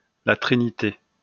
来自 Lingua Libre 项目的发音音频文件。 语言 InfoField 法语 拼写 InfoField La Trinité 日期 2019年9月30日 来源 自己的作品